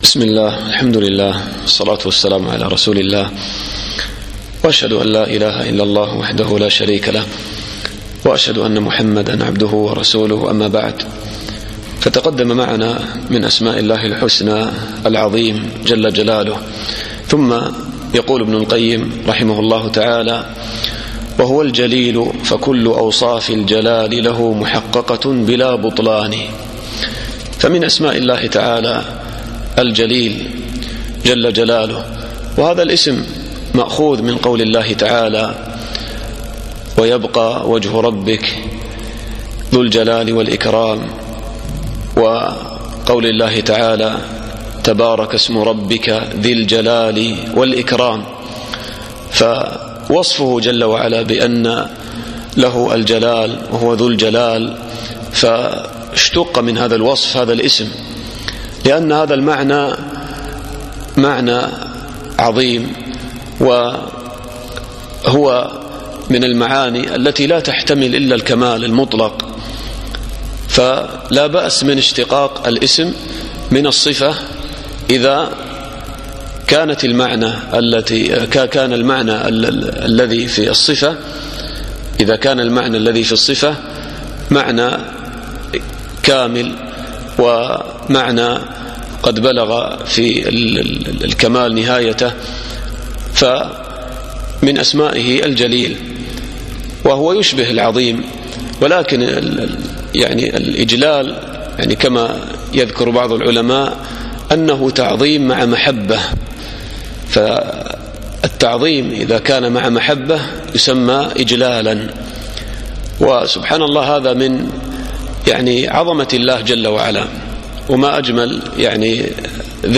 الدرس الخامس